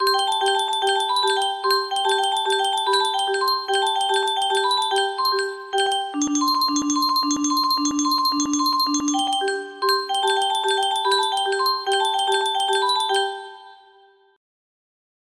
Music???? music box melody
Wow! It seems like this melody can be played offline on a 15 note paper strip music box!